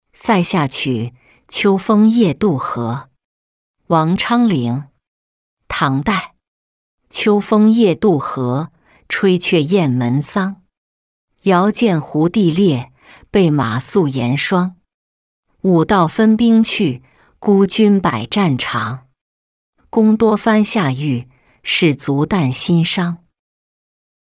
塞下曲·秋风夜渡河-音频朗读